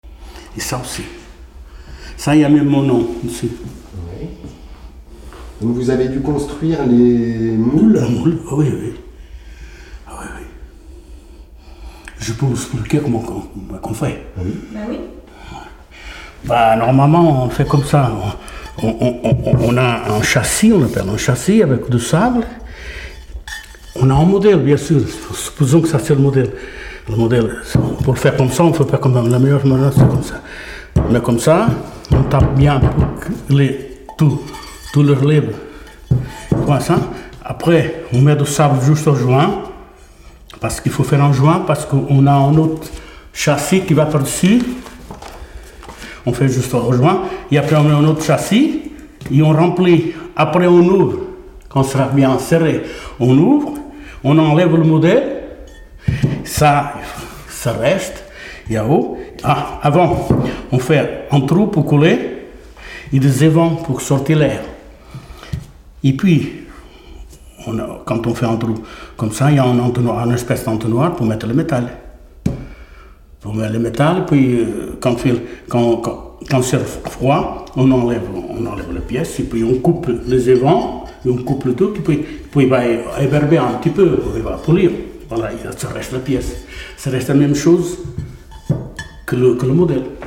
Figure 07 : Extrait vidéo du témoignage d’un fondeur.